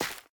Minecraft Version Minecraft Version 1.21.5 Latest Release | Latest Snapshot 1.21.5 / assets / minecraft / sounds / block / hanging_roots / break4.ogg Compare With Compare With Latest Release | Latest Snapshot
break4.ogg